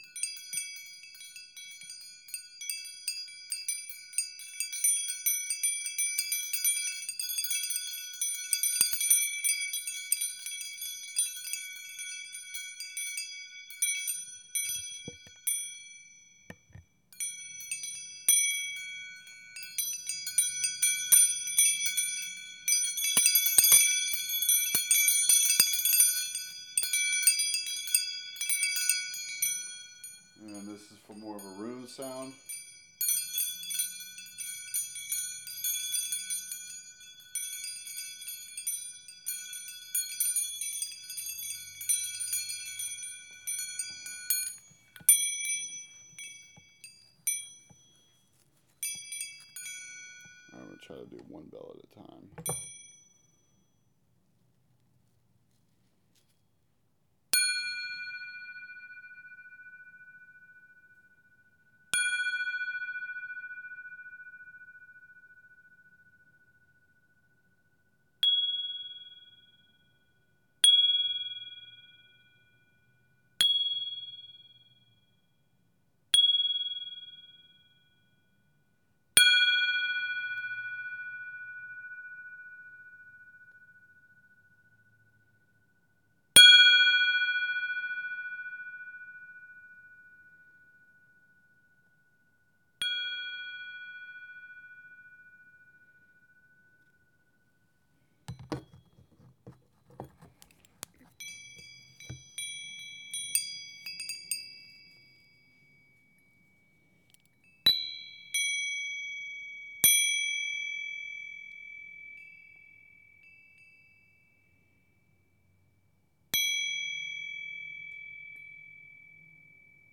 tibetan bells wind chime ring meditation ohm
bell bells bell-tone bronze Buddhism chime ding meditation sound effect free sound royalty free Sound Effects